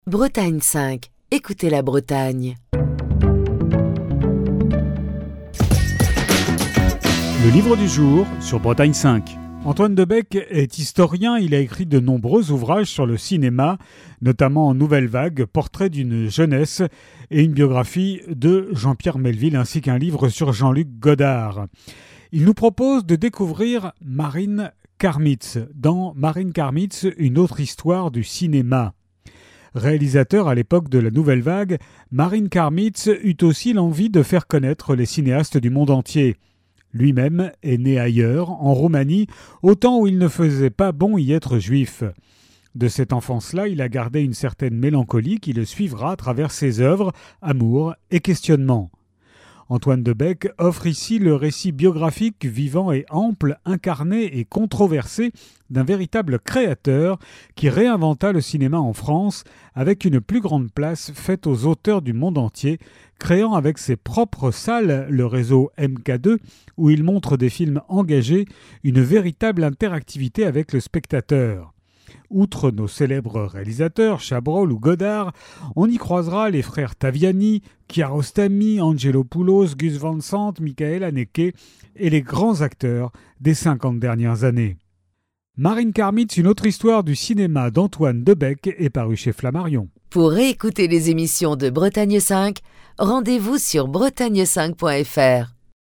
Chronique du 14 octobre 2024.